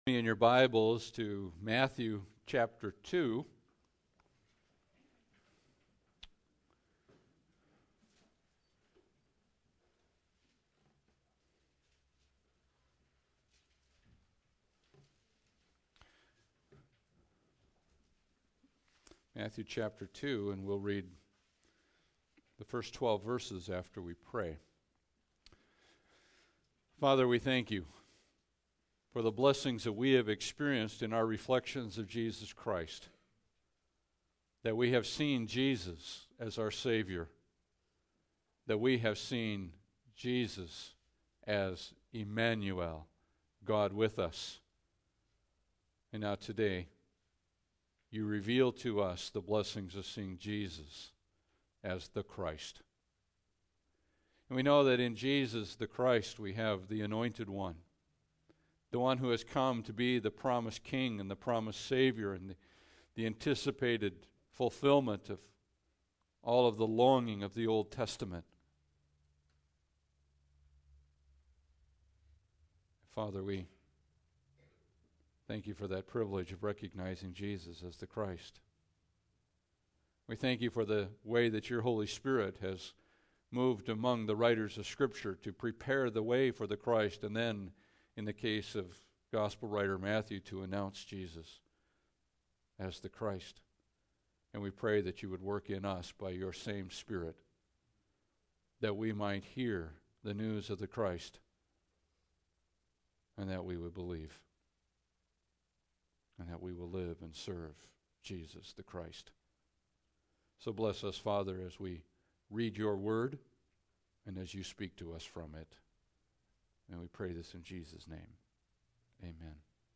Passage: Matthew 2:1-12 Service Type: Sunday Service